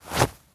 foley_cloth_light_fast_movement_08.wav